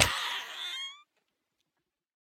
Minecraft Version Minecraft Version 1.21.5 Latest Release | Latest Snapshot 1.21.5 / assets / minecraft / sounds / mob / turtle / baby / death2.ogg Compare With Compare With Latest Release | Latest Snapshot